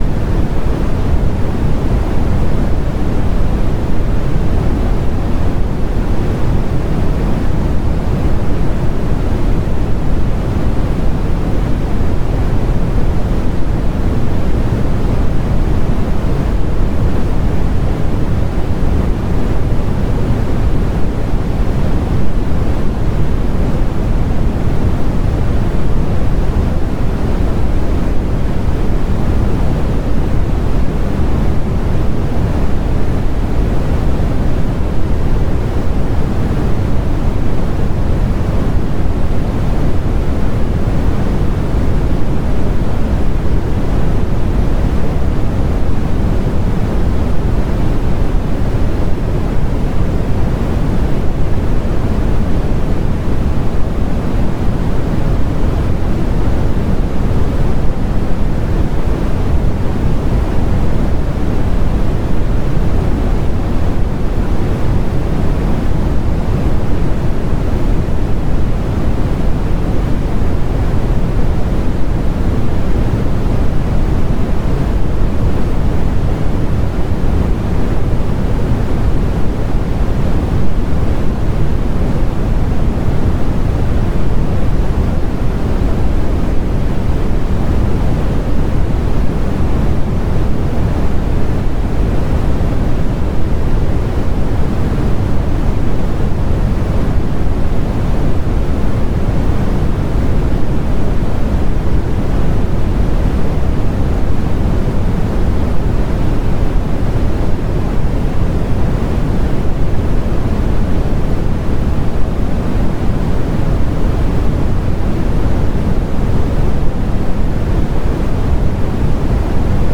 ssc_thruster2w.wav